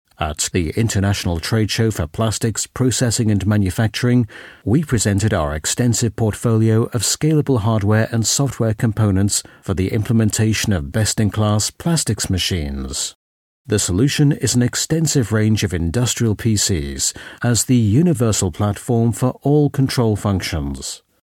• Der Englischer Sprecher
Native Speaker
Imagefilme